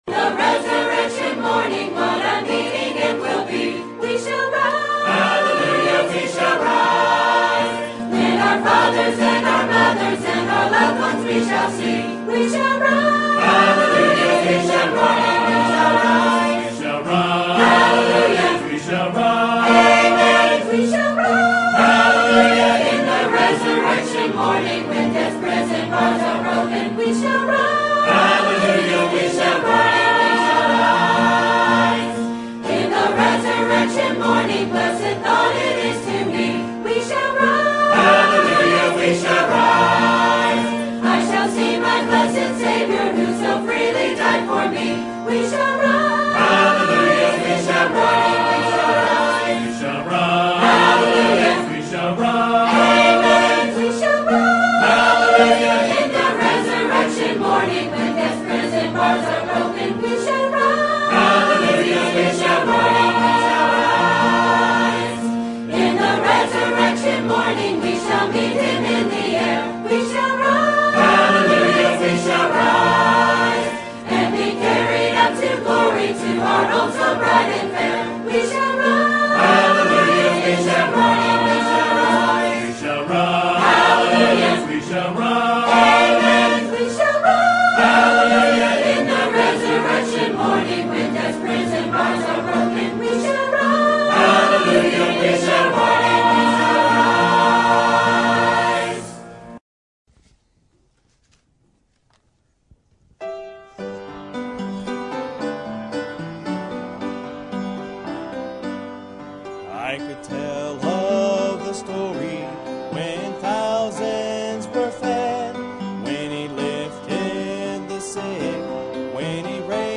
Sermon Topic: Baptist History Conference Sermon Type: Special Sermon Audio: Sermon download: Download (45.45 MB) Sermon Tags: Acts Baptist History Liberty